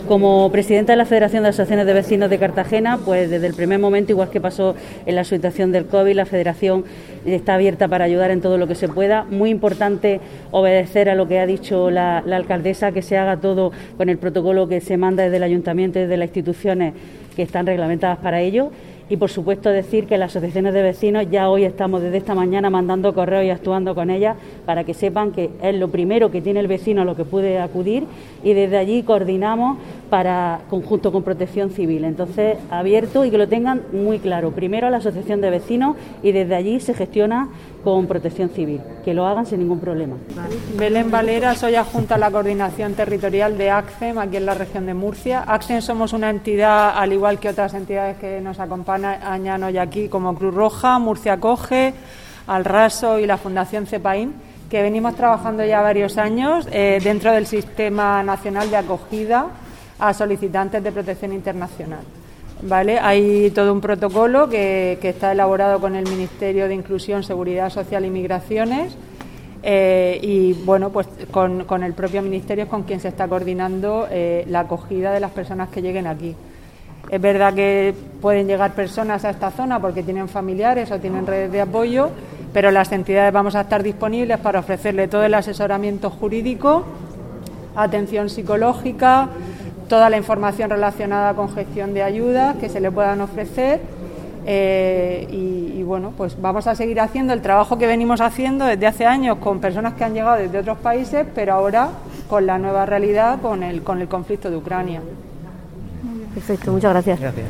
Audio: Declaraciones de Noelia Arroyo (MP3 - 2,46 MB)